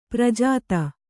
♪ prajāta